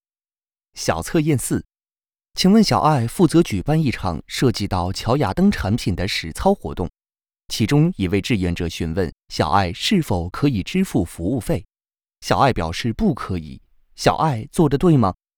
Chinese_Male_005VoiceArtist_20Hours_High_Quality_Voice_Dataset
Text-to-Speech